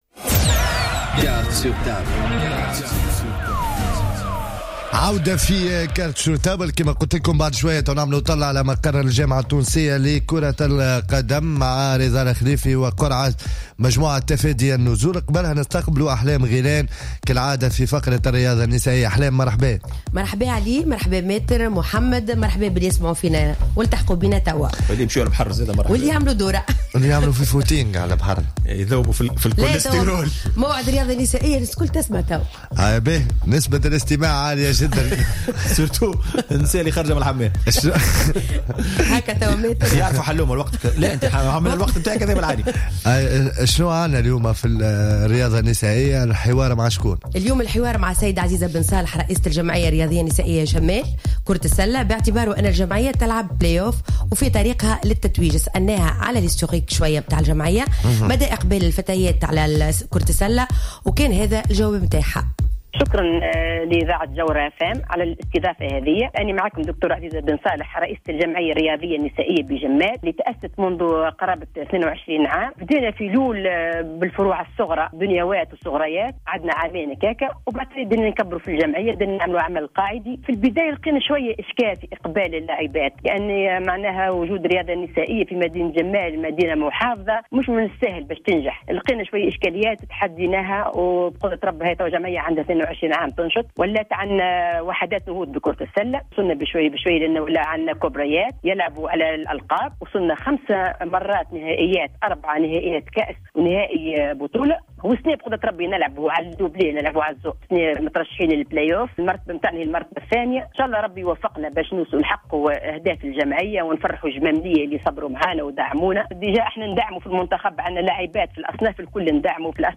تدخل هاتفيا